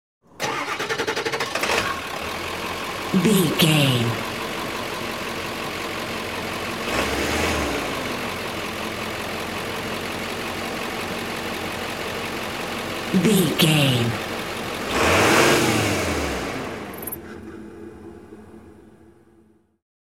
Ambulance Ext Diesel Engine Turn on off
Sound Effects
urban
emergency